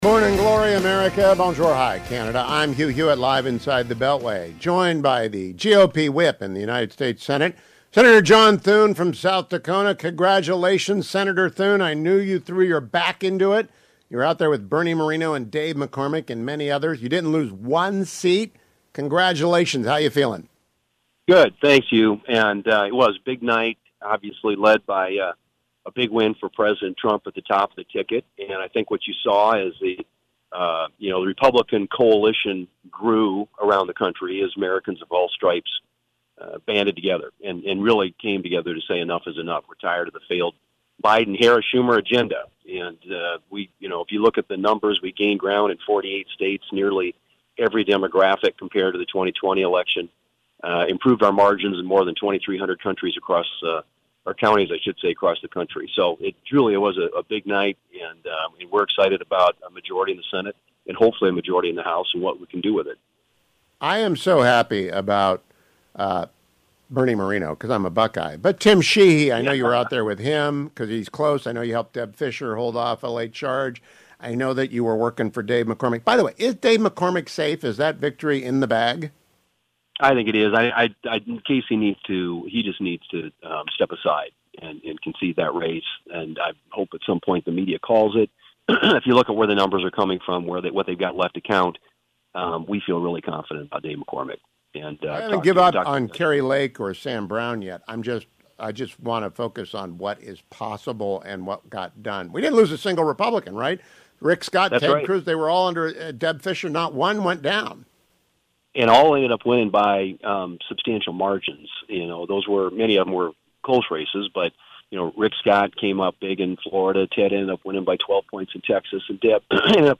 Senate GOP Whip John Thune joined me this morning: